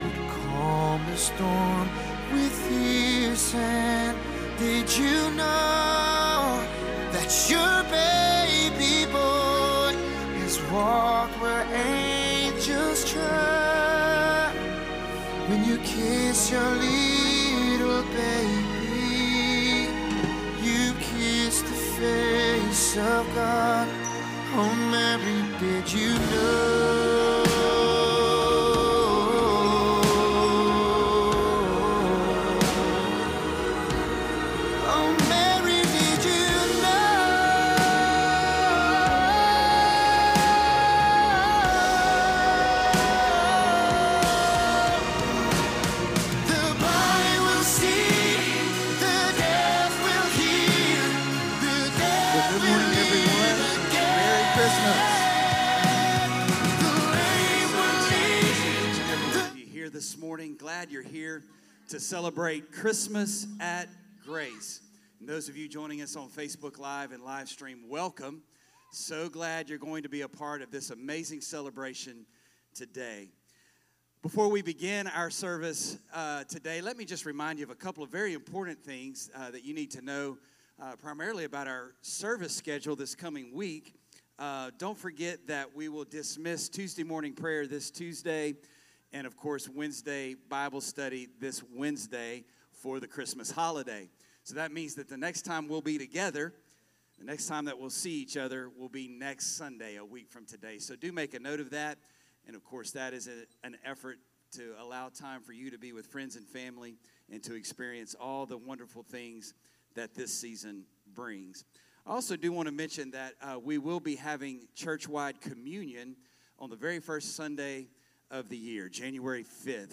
1 Grace Church Worship Service